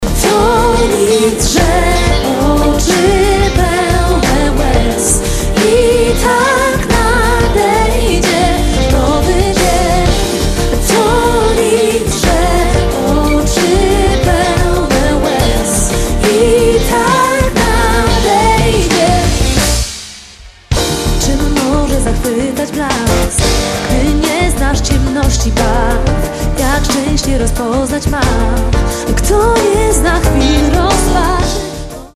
live
rejestracja koncertu
wokal
chórek
klawisze
gitara
perkusja